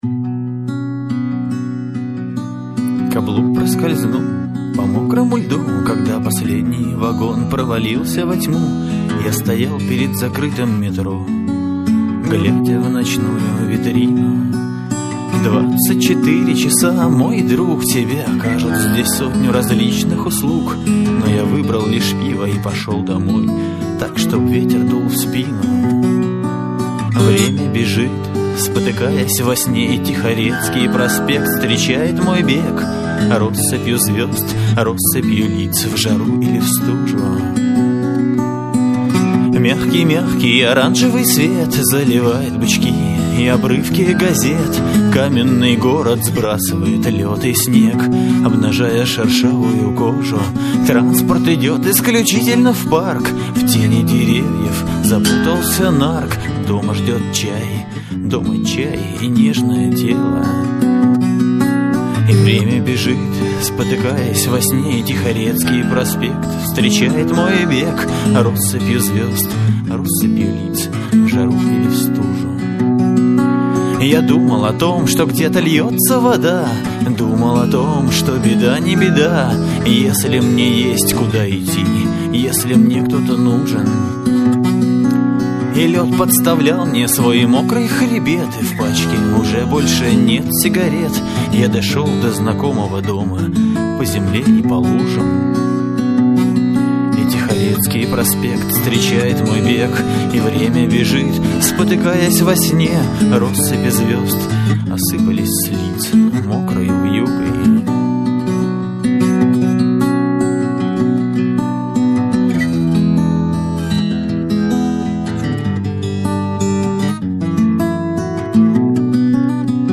112 kbps, stereo, studio sound. audio live